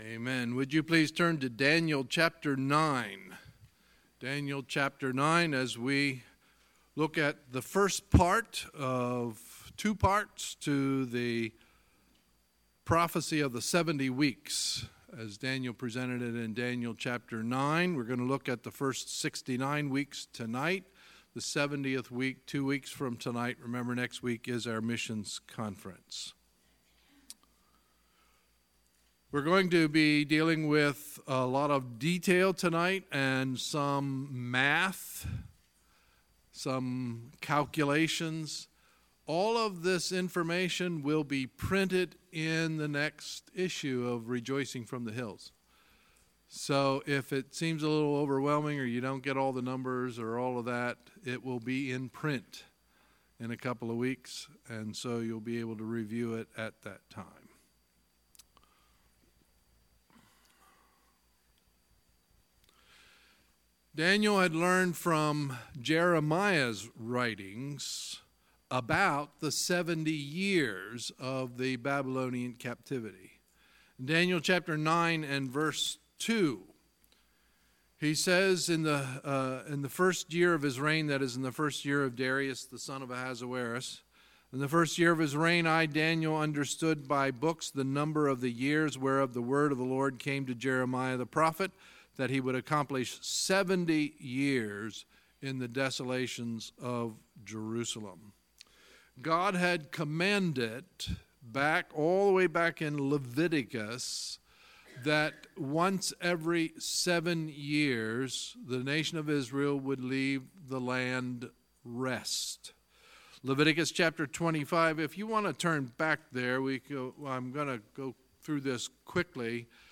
Sunday, September 16, 2018 – Sunday Evening Service